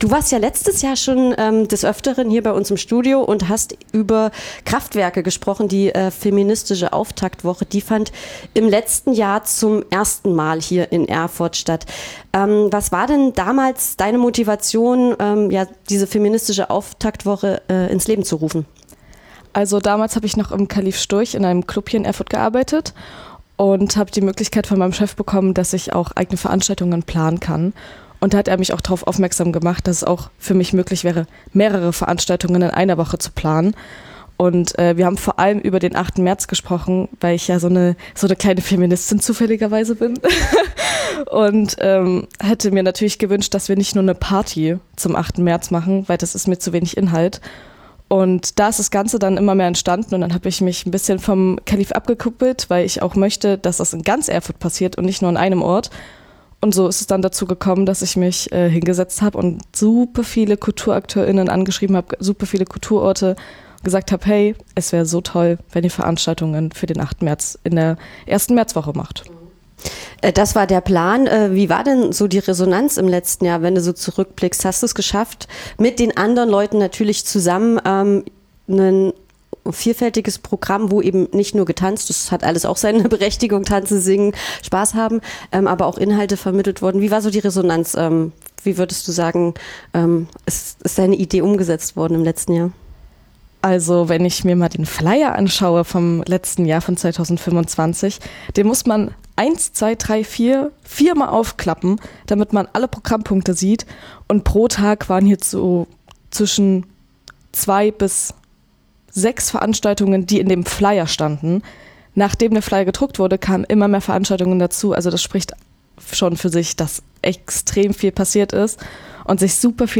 �Durch Kultur Feminismus erlebbar machen� | Interview zur feministischen Auftaktwoche 2026